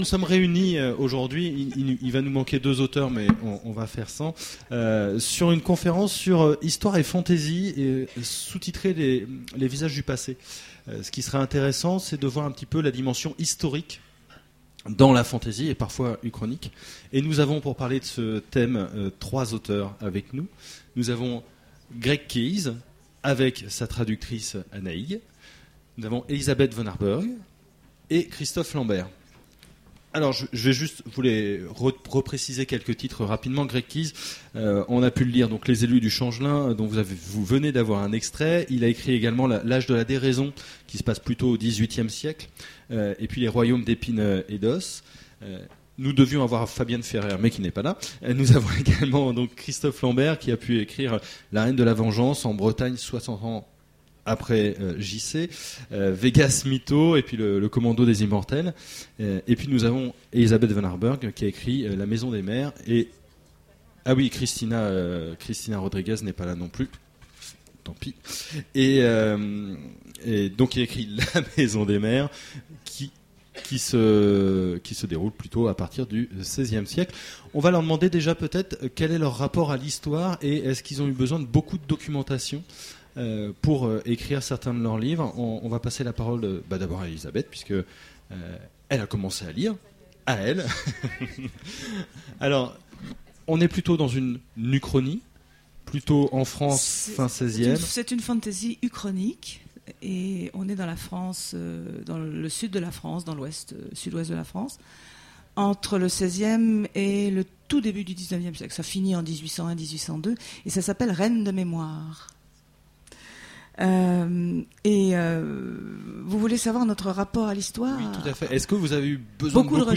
Voici l'enregistrement de la conférence Histoire ou fantasy. Les visages du passé… aux Imaginales 2010